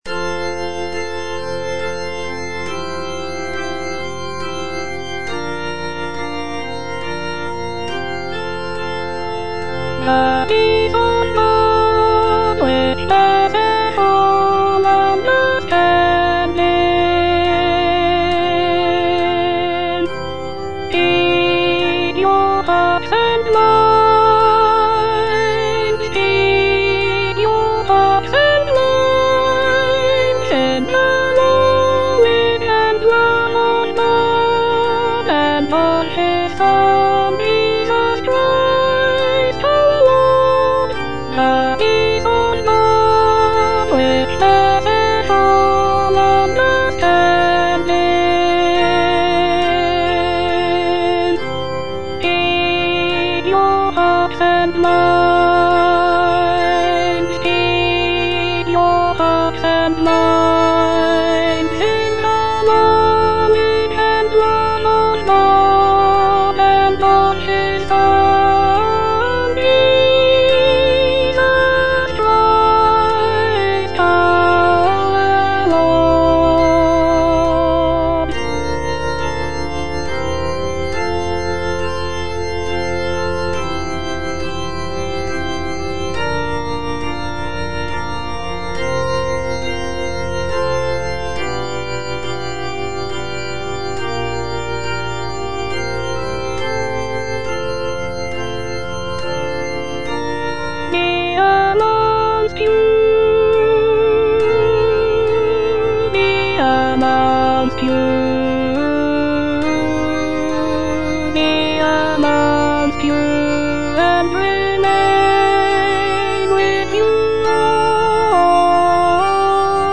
Alto (Voice with metronome)
choral anthem